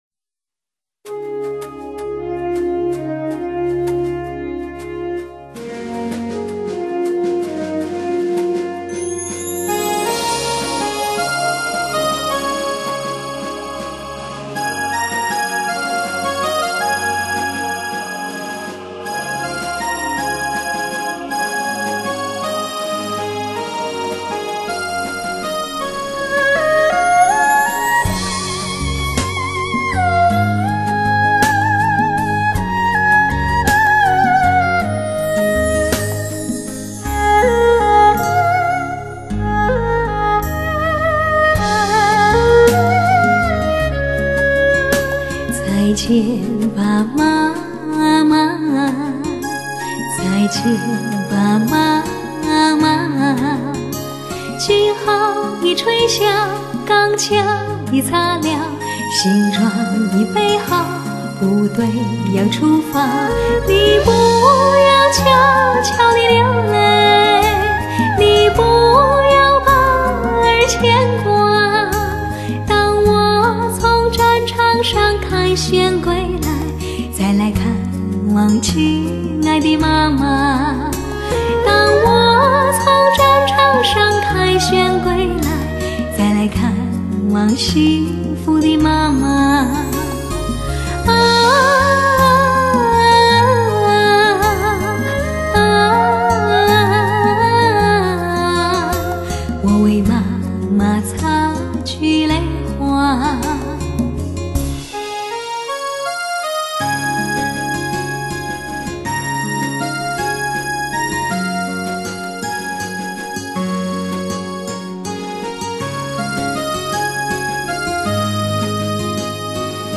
庆祝建国60周年经典颂歌倾情奉献！
绽放的旋律由内而外，激情澎湃。